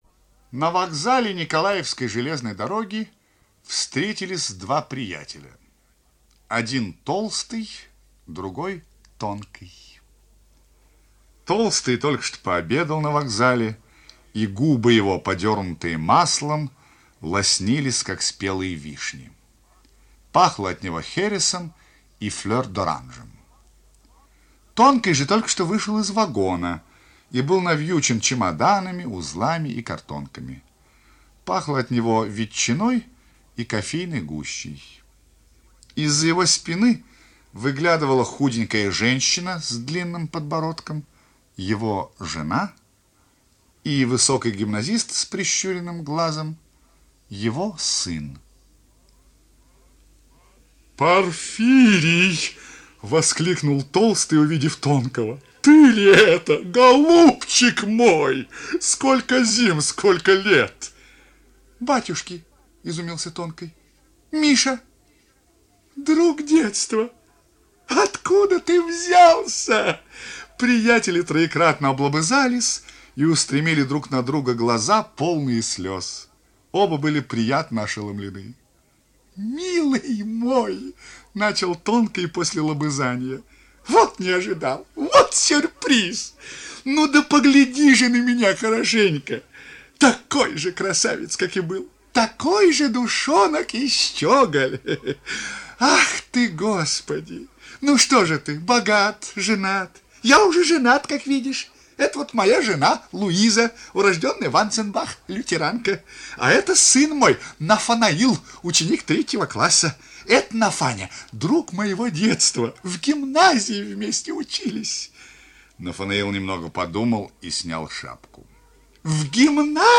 Антон Чехов. Толстый и тонкий (аудиорассказ)
Читает : Ростислав Плятт